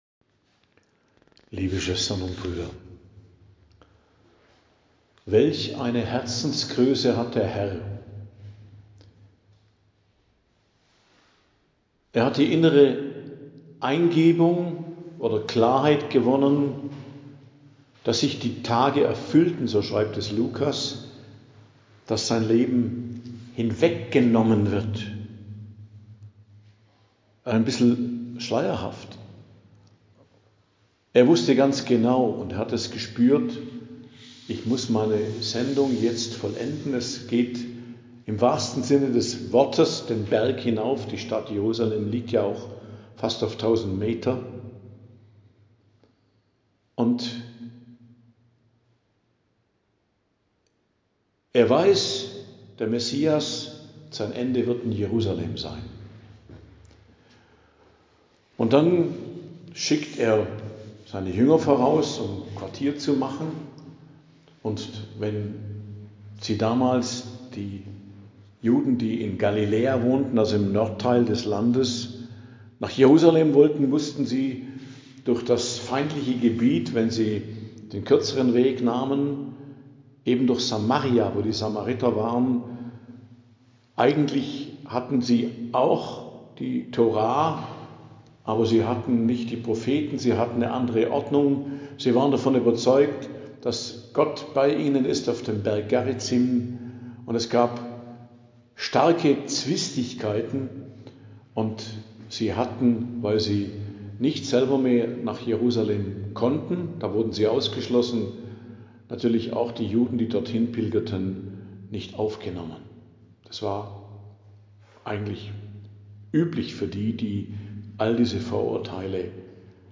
Predigt am Dienstag der 26. Woche i.J., 30.09.2025